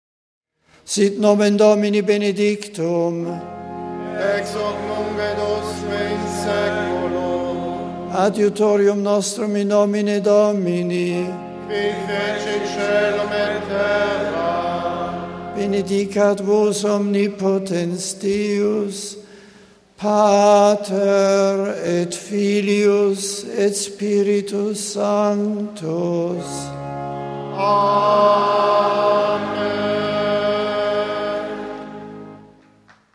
The general audience of Nov. 3 was held indoors in the Vatican’s Paul VI audience hall.
Pope Benedict then delivered a discourse in English.